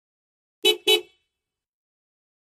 VEHICLES - HORNS 1999 TOYOTA CAMRY: Car horn, 2 toots, Toyota Camry.